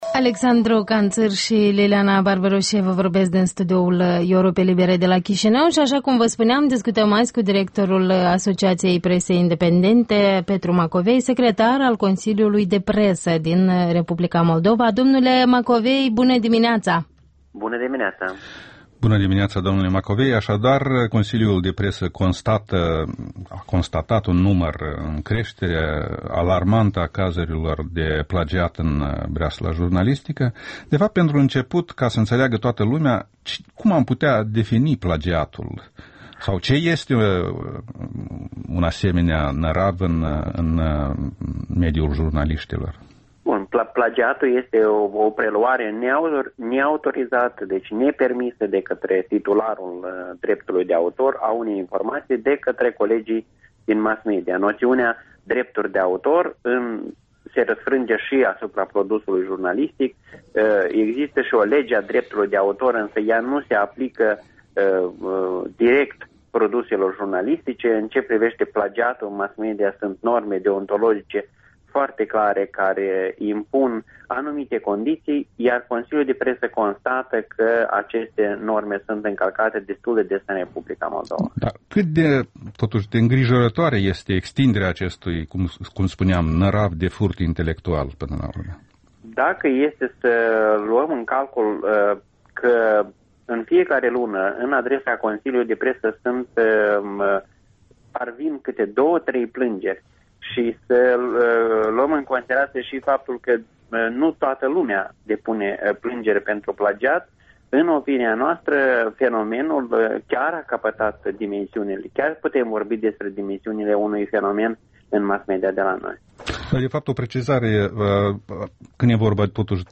Interviul dimineţii la Europa Liberă